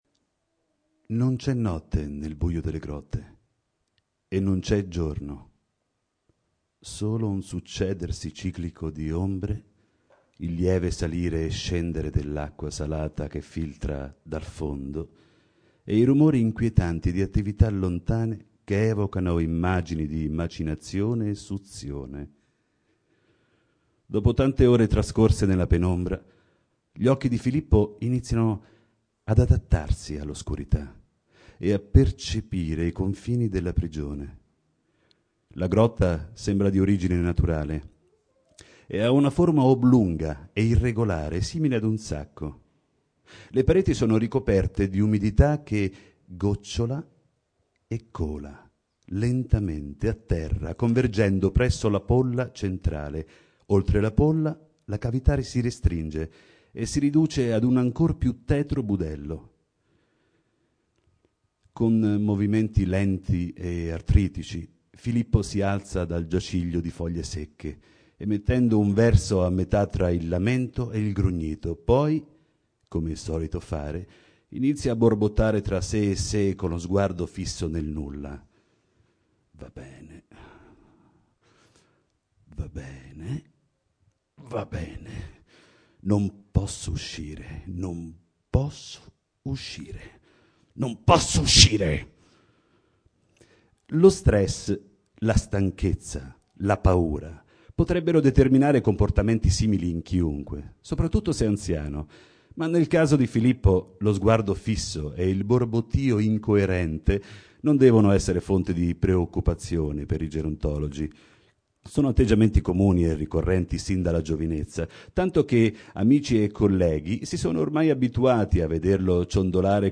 Letture di brani scelti